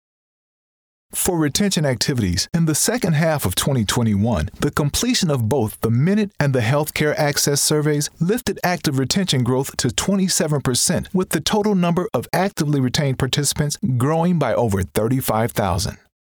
Inglês (dos Estados Unidos)
Demonstração Comercial
Tudo isso foi entregue com "uma voz envolvente e rica".
Cabine de gravação Studio Bricks
Microfone Sennheiser 416